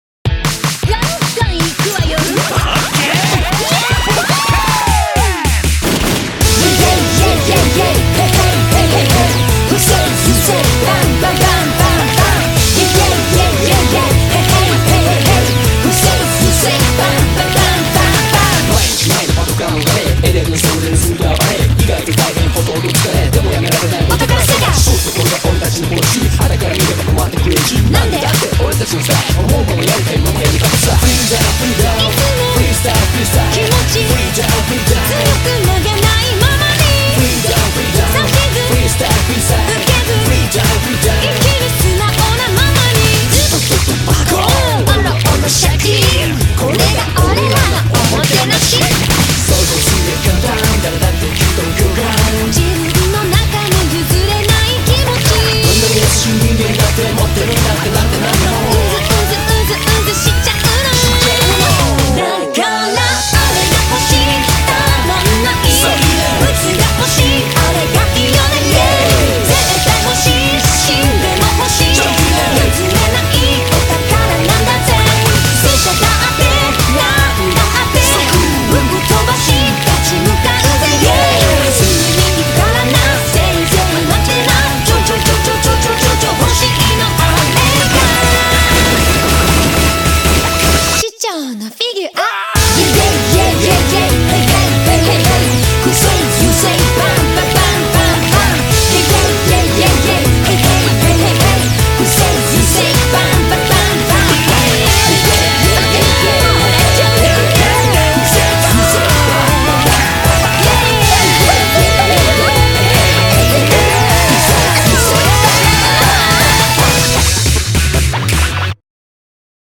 BPM156
Audio QualityMusic Cut